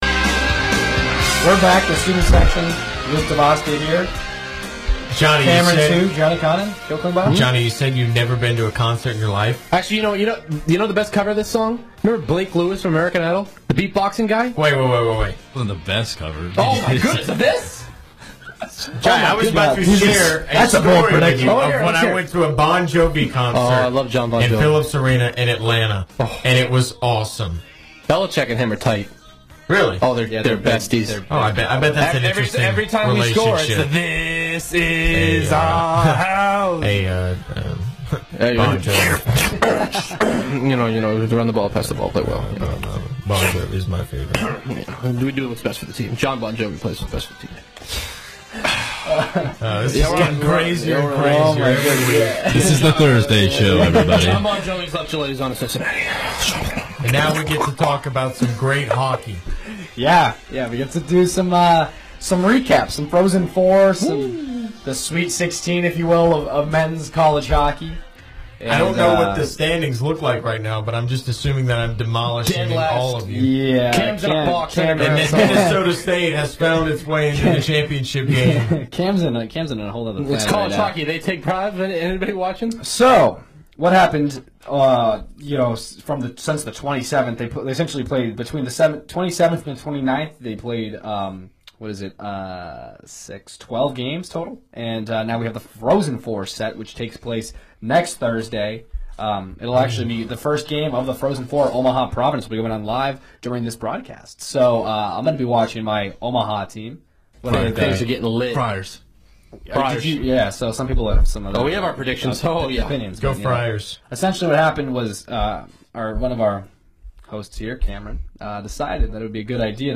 WVUA-FM's and Tuscaloosa's longest running sports talk show "The Student Section"